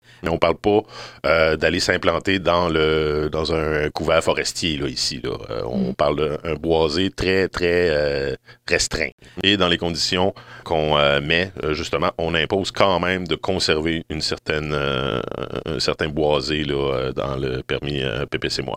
En ce qui a trait à la destruction du boisé qu’impliquerait la construction des logements sur les deux terrains, le maire se veut rassurant: